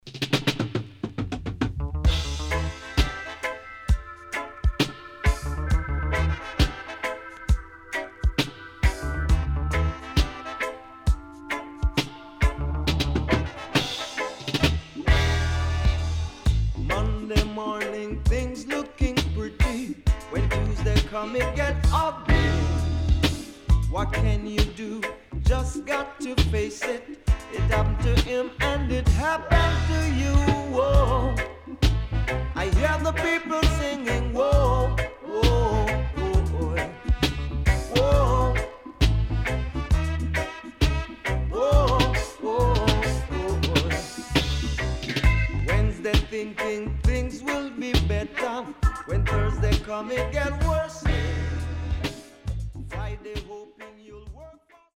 HOME > LP [DANCEHALL]  >  EARLY 80’s
SIDE B:少しノイズ入りますが良好です。